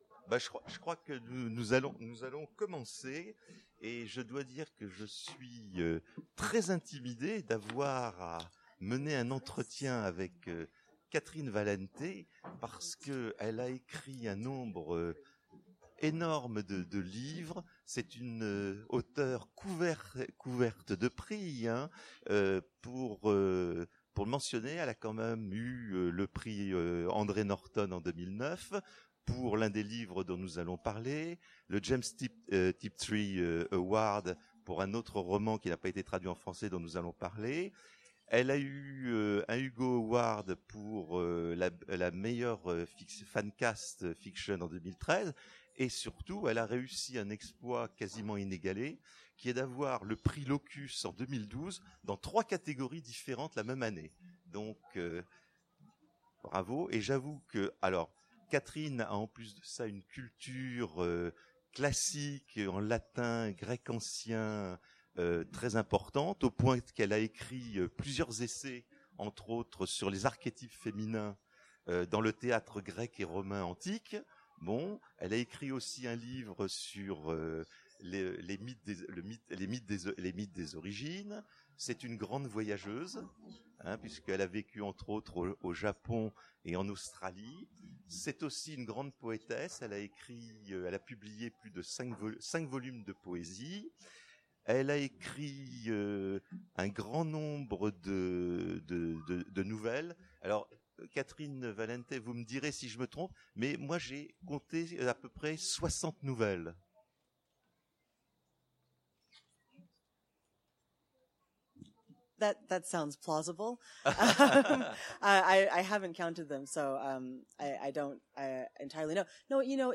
Imaginales 2017 : Entretien avec Catherynne M. Valente
- le 31/10/2017 Partager Commenter Imaginales 2017 : Entretien avec Catherynne M. Valente Télécharger le MP3 à lire aussi Catherynne M. Valente Genres / Mots-clés Rencontre avec un auteur Conférence Partager cet article